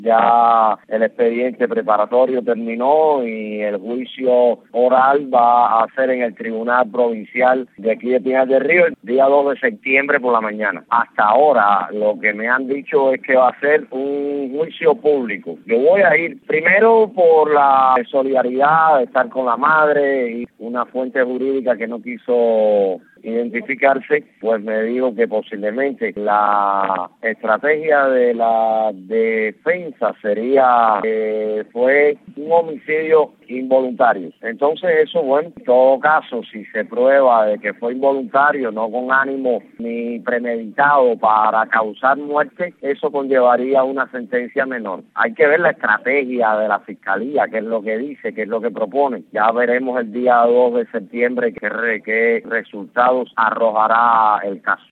Radio Martí hizo contacto en Pinar del Río con el periodista, quien proporcionó otros detalles..